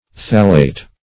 thallate - definition of thallate - synonyms, pronunciation, spelling from Free Dictionary
thallate - definition of thallate - synonyms, pronunciation, spelling from Free Dictionary Search Result for " thallate" : The Collaborative International Dictionary of English v.0.48: Thallate \Thal"late\, n. (Chem.) A salt of a hypothetical thallic acid.